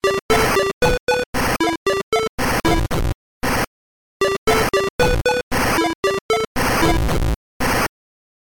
big-booty-judy-drums.ogg